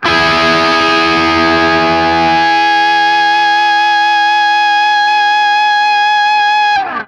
TRIAD E   -L.wav